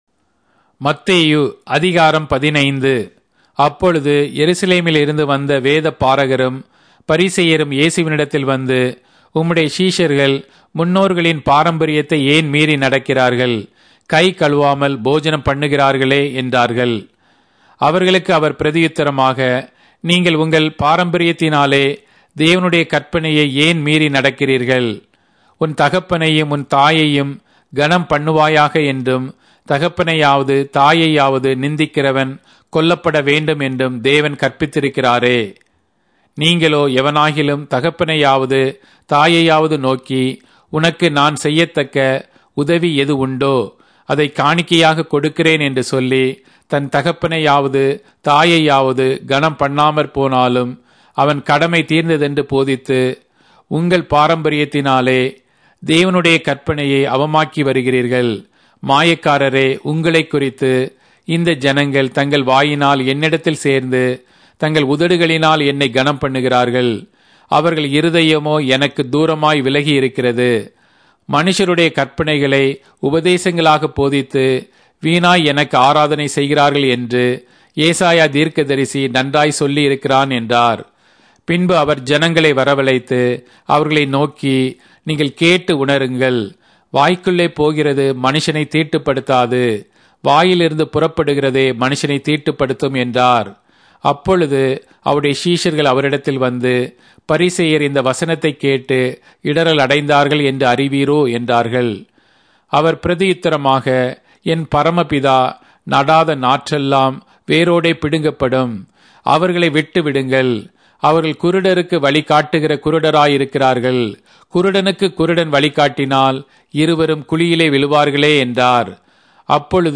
Tamil Audio Bible - Matthew 4 in Gnttrp bible version